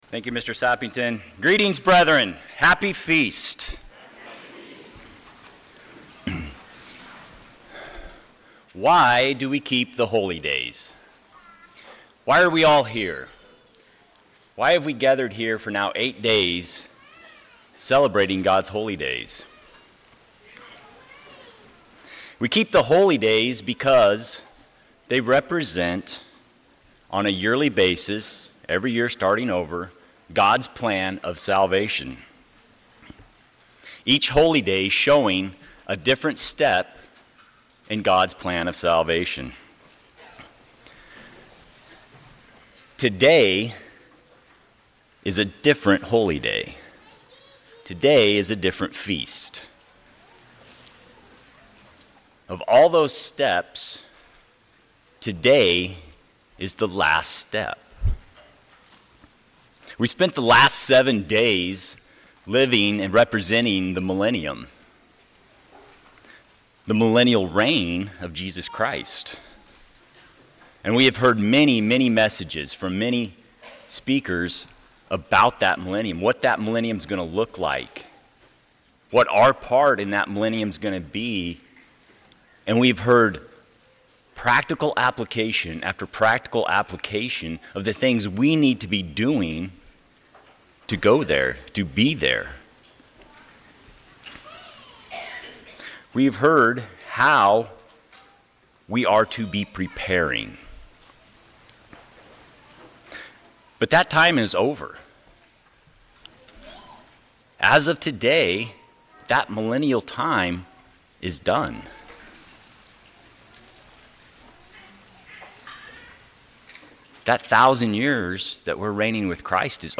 Given in Lihue, Hawaii